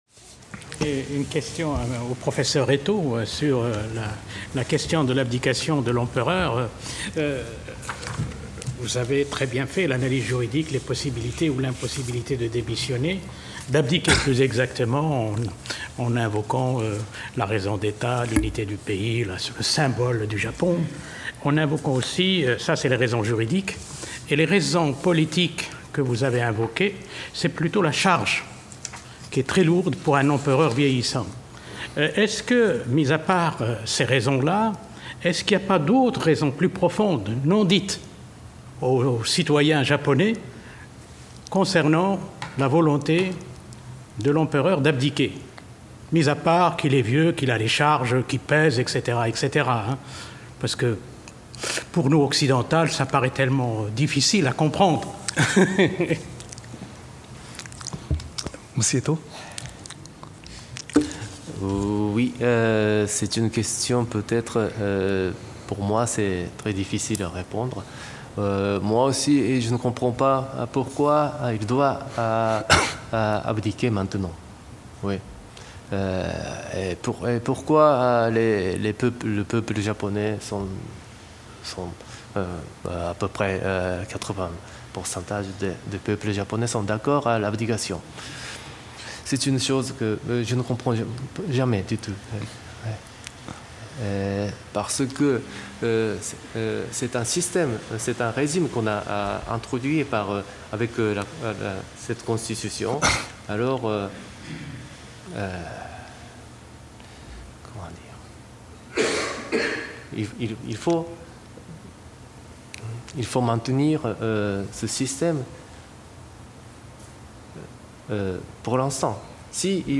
Colloque Nihon Europa, mercredi 5 octobre 2016, discussion de la matinée | Canal U